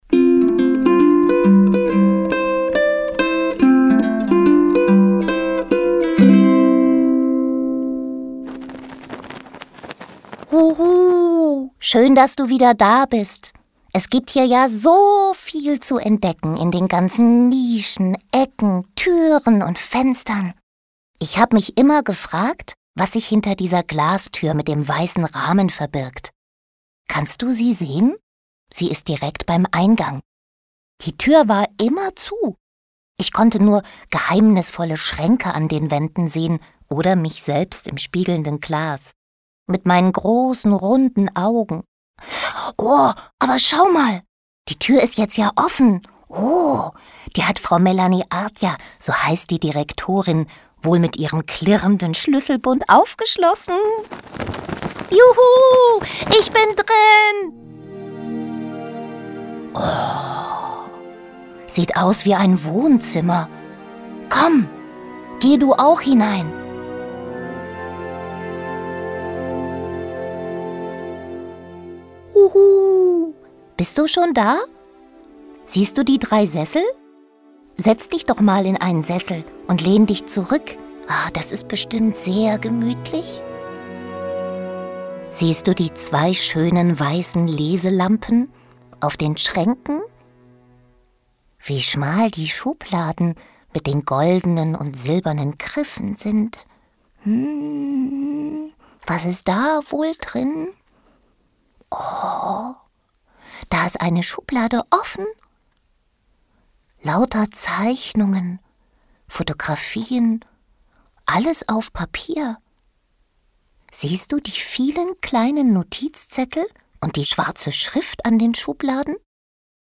Der KuHu darf das erste Mal in den Salone - Audioguide für Kinder
audioguide-kinder-07-salone.mp3